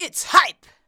ITS HYPE.wav